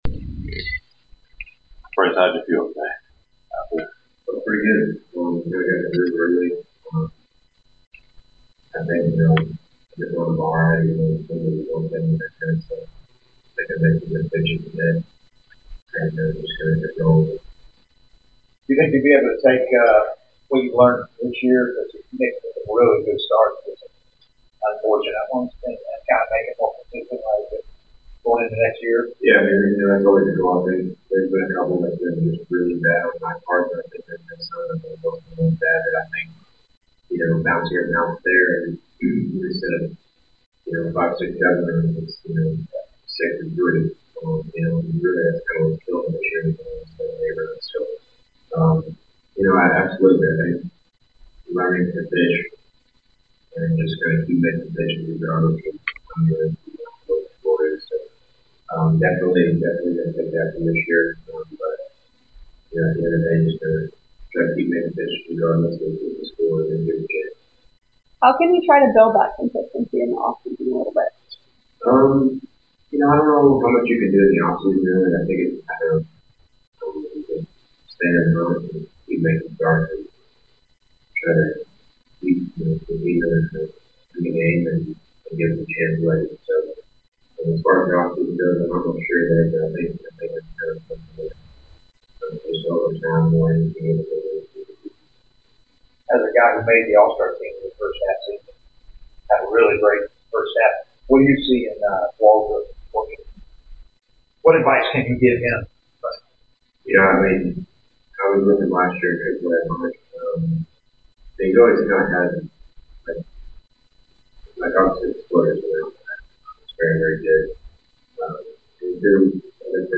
Atlanta Braves Pitcher Bryce Elder Postgame Interview after defeating the New York Mets at Truist Park.